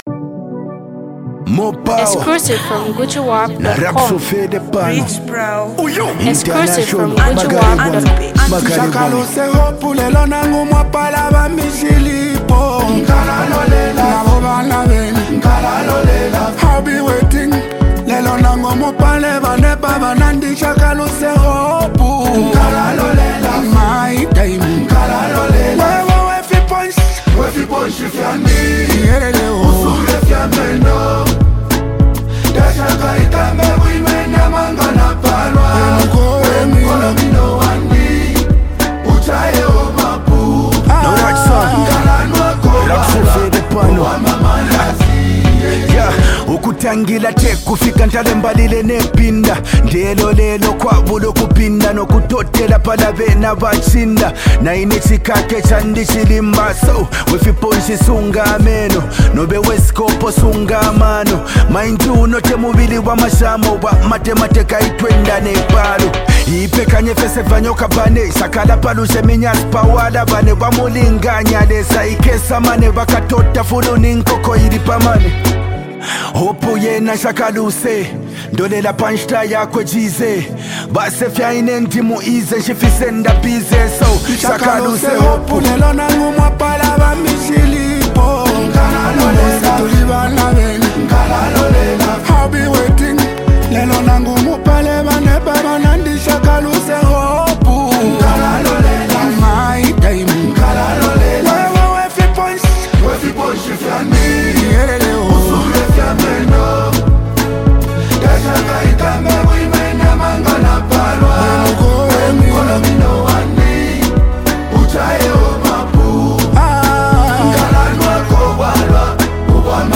is one another Superb afro hit song